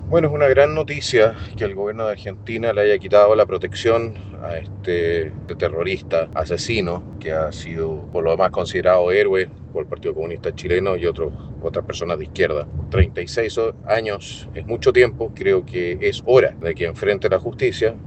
En el Congreso, el diputado del Partido Nacional Libertario (PNL), Johannes Kaiser, calificó como una “gran noticia” que Argentina le quitara la protección al exfrentista, sosteniendo que después de más de tres décadas “es hora de que enfrente la justicia”.